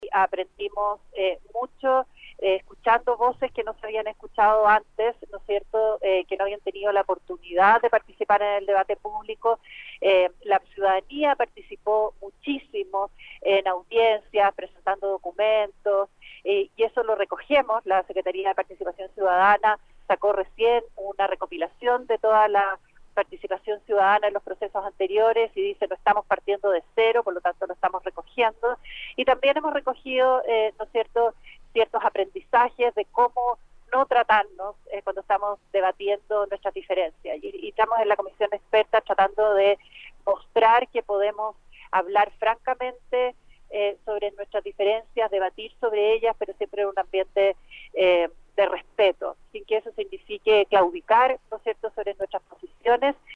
Para cumplir con la meta, la abogada afirmó en Radio UdeC que será fundamental mantener el “diálogo franco, abierto y respetuoso.